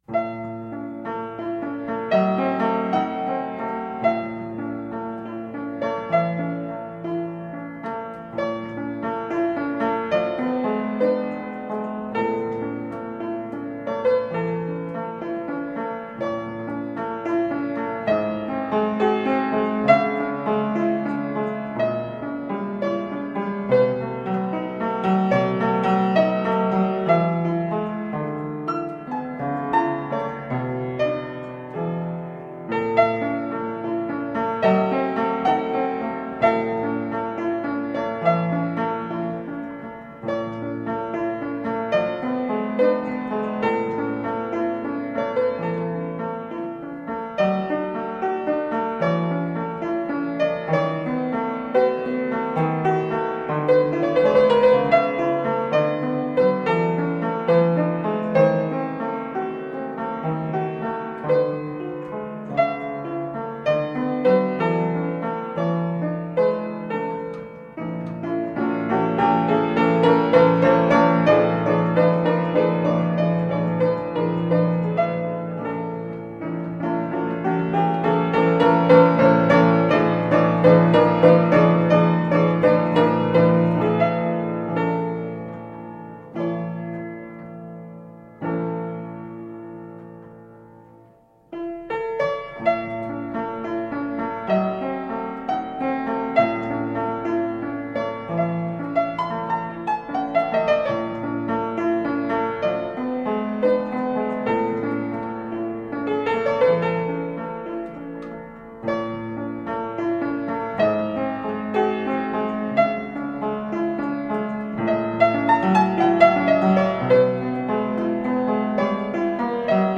Marvelously played classical piano pieces.
Tagged as: Classical, Instrumental Classical, Piano